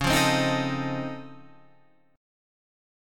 Db+M9 chord